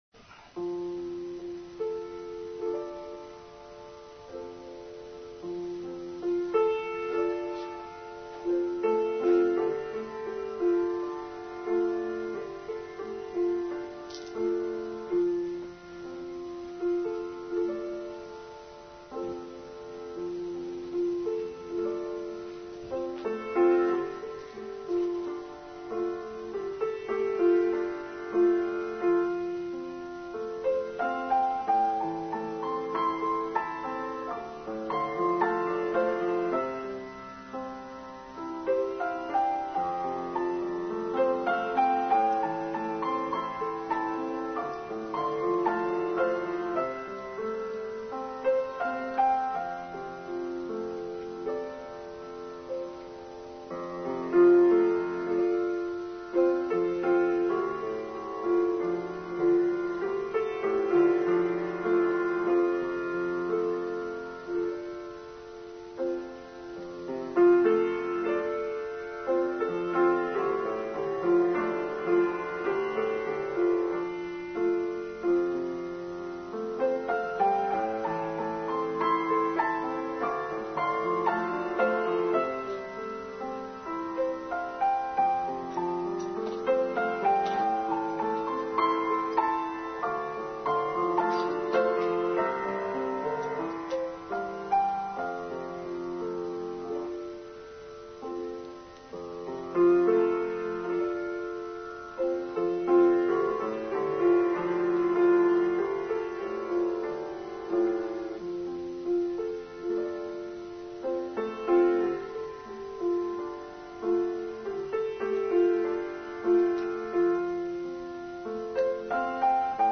Online service for 13th September: Death